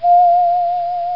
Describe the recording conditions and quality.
Download a high-quality human whistle sound effect.